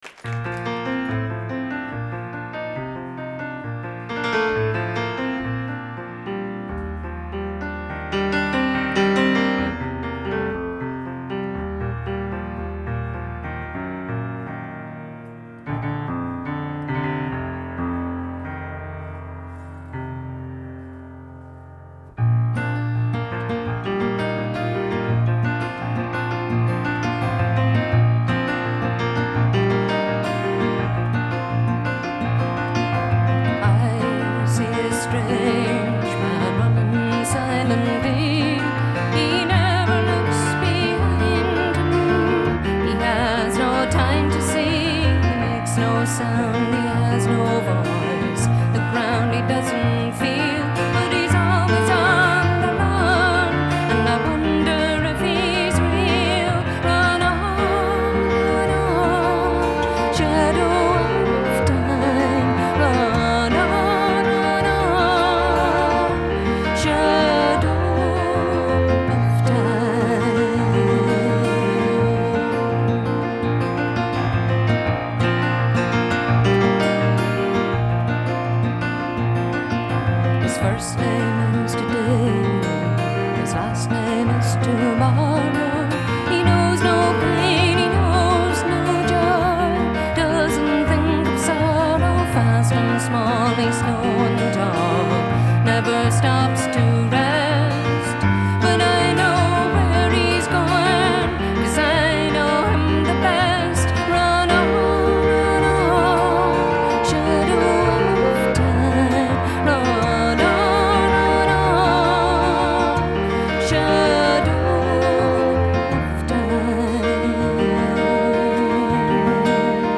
爵士/世界音乐
主奏乐器：人声、民族乐器
让传统民谣显得轻盈灵巧，
其余的曲子是在俄勒冈波特兰的White Horse Studio录音室录制的。